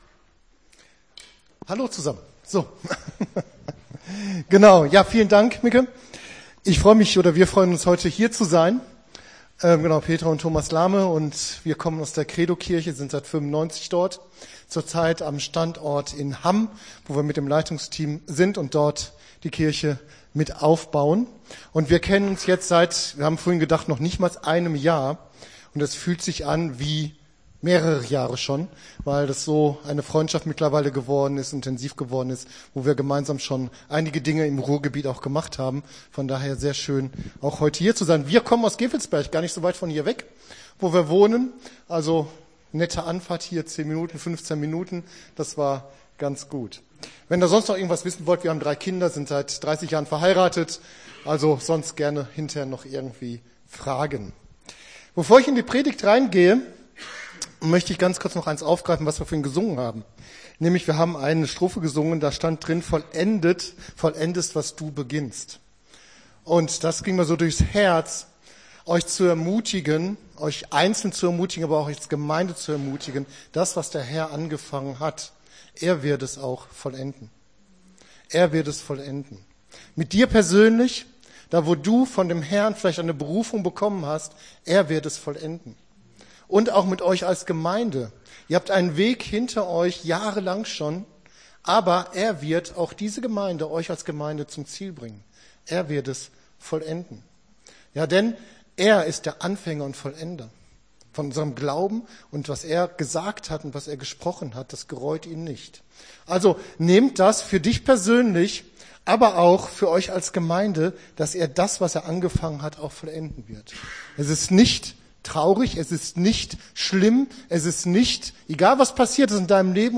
Gottesdienst 11.12.22 - FCG Hagen